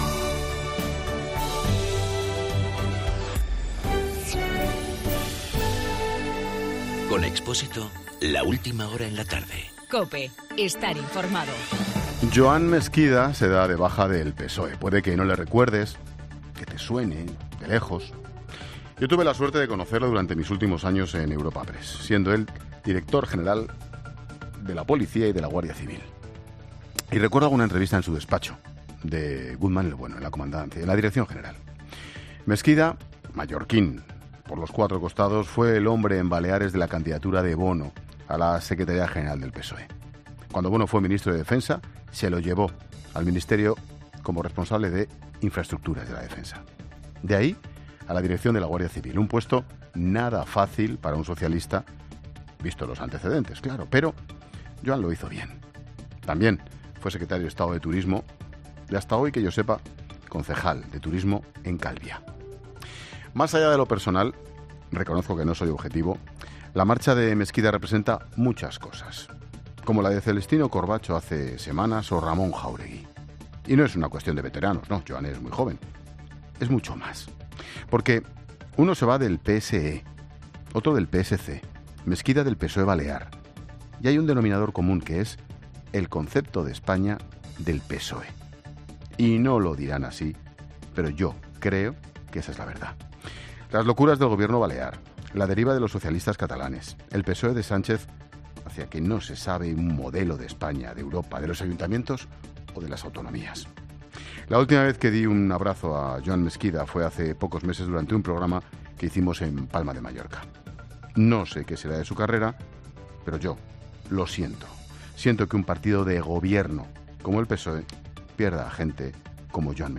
Monólogo de Expósito
El comentario de Ángel Expósito tras la marcha de Joan Mesquida del PSOE.